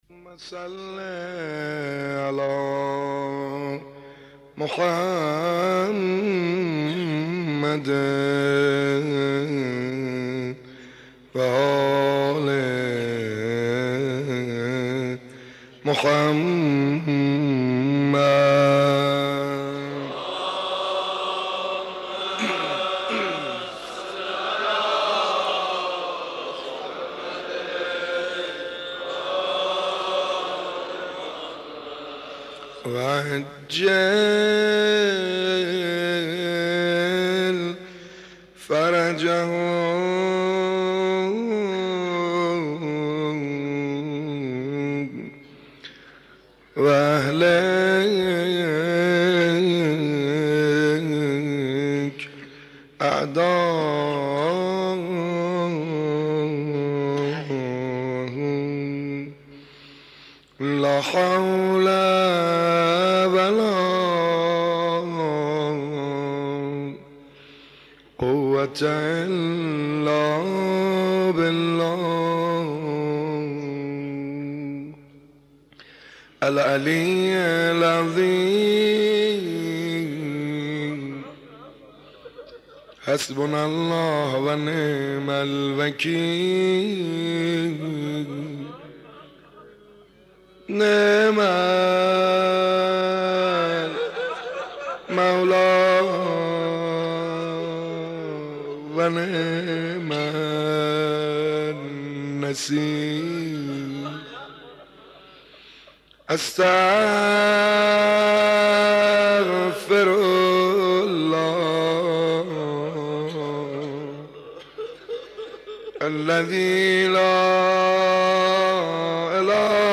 مناسبت : تاسوعای حسینی
مداح : سعید حدادیان